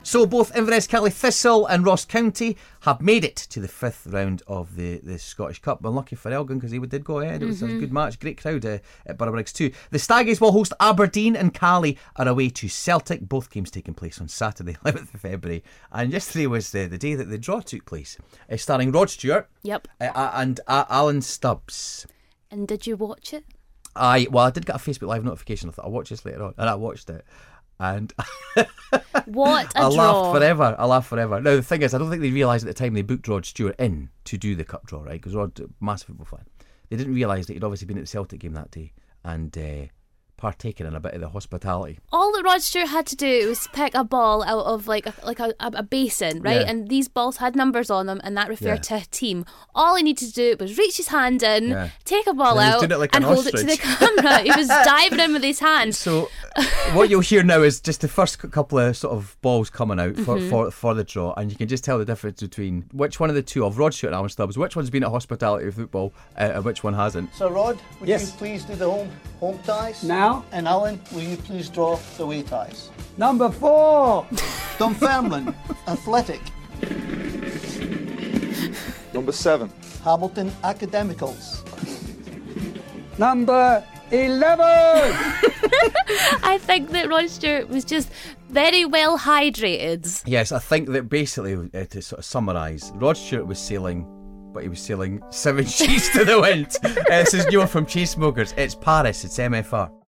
A very merry Sir Rod Stewart helps choose the teams for the Scottish Cup.